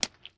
STEPS Pudle, Walk 02, Single Impact.wav